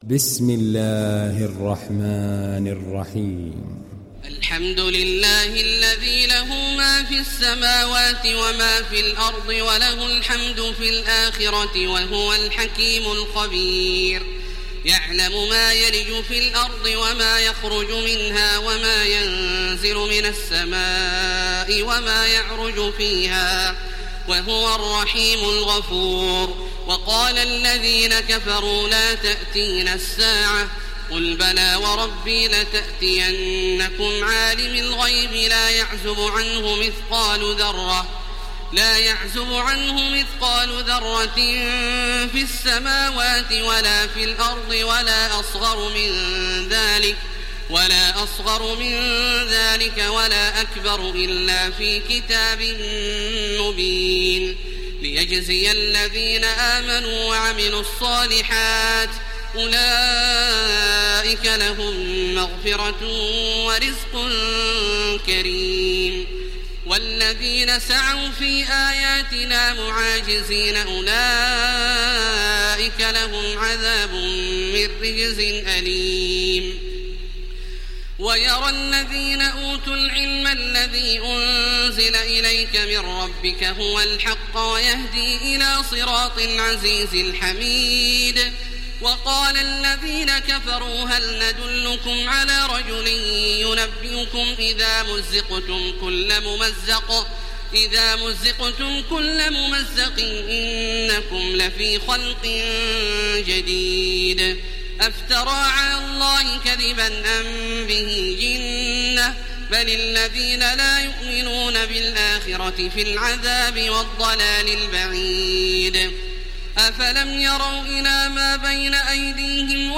Download Surat Saba Taraweeh Makkah 1430